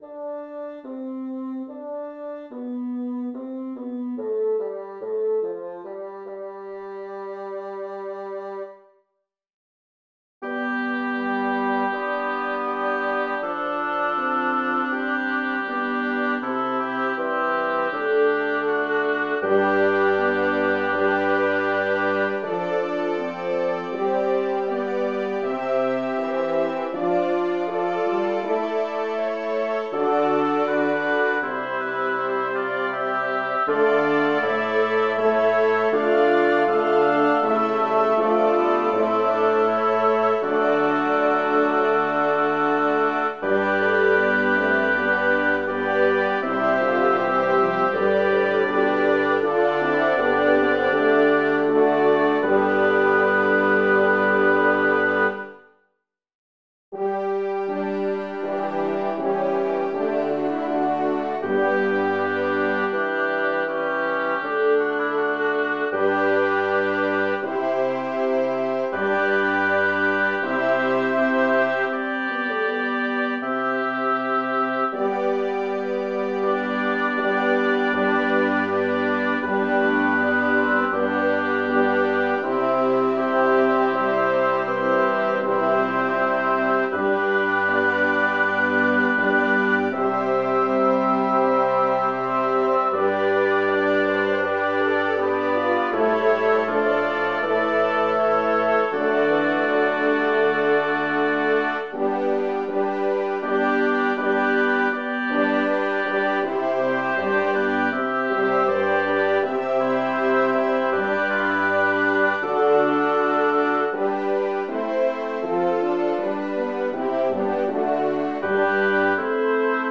Title: Ecce panis Angelorum Composer: Peter Philips Lyricist: Thomas Aquinas Number of voices: 8vv Voicing: SATB.SATB Genre: Sacred, Motet, Eucharistic song
Language: Latin Instruments: A cappella